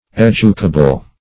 educable - definition of educable - synonyms, pronunciation, spelling from Free Dictionary
educable \ed"u*ca*ble\ ([e^]d"[-u]*k[.a]*b'l; 135), a. [Cf. F.